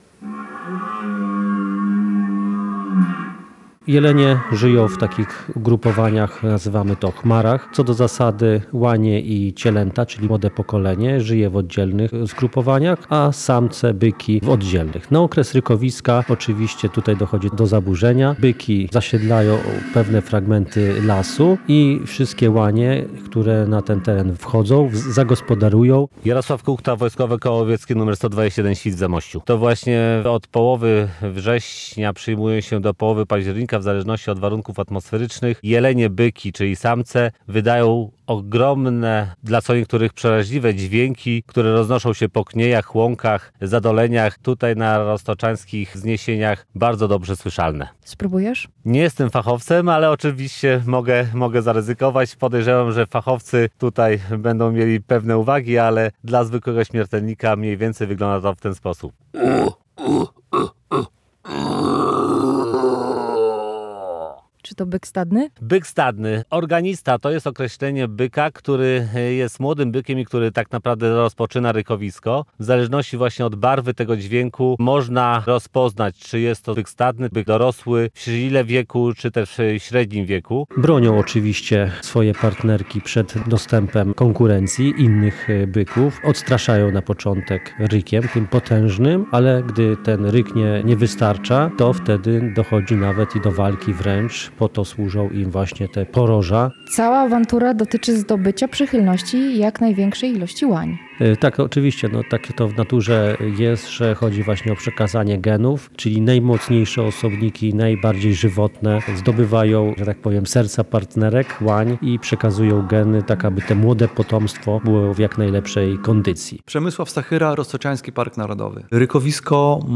W lasach Roztocza i Zamojszczyzny trwają wokalne popisy jeleni szlachetnych. Rykowisko to czas, kiedy żyjące osobno łanie i jelenie spotykają się w jednym miejscu i łączą się w chmary, by dać życie kolejnym pokoleniom.
– Od połowy września do połowy października samce jeleni wydają ogromne, a dla niektórych przeraźliwe dźwięki, które roznoszą się po kniejach i łąkach.